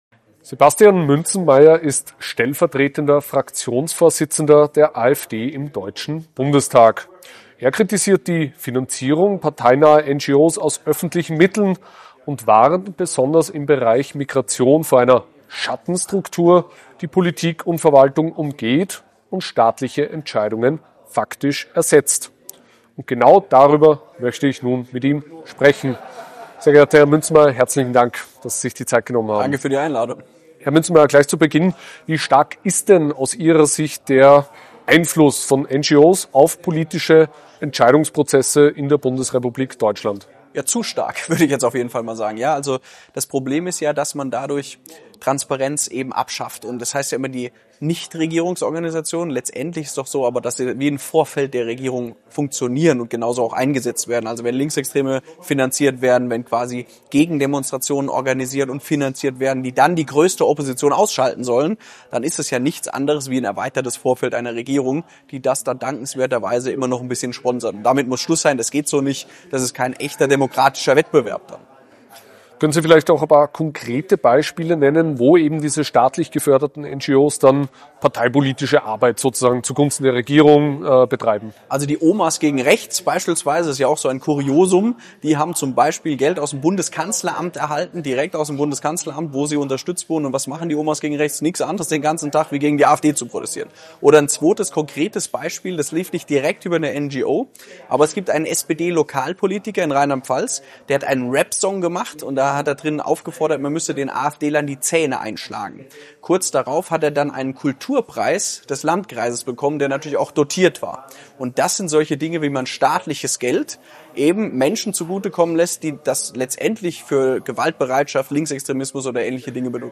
AfD-Fraktionsvize Sebastian Münzenmaier spricht im AUF1-Interview